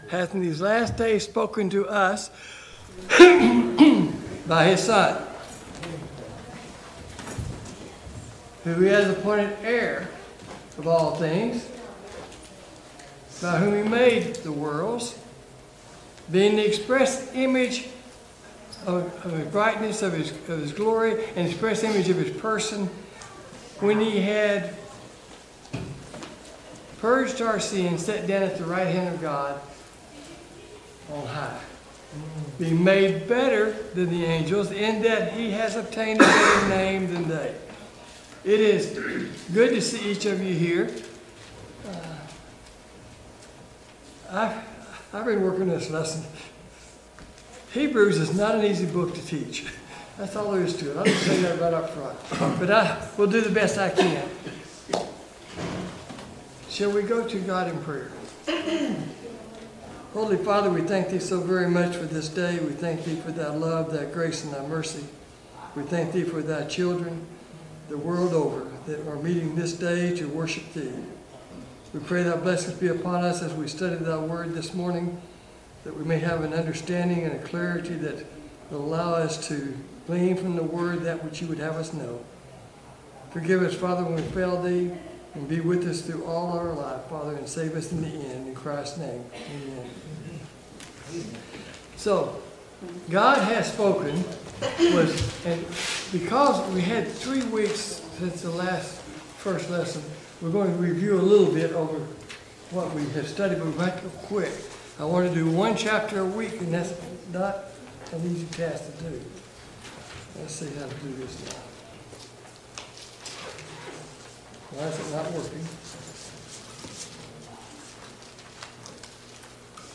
Hebrews 2 Service Type: Sunday Morning Bible Class « 21.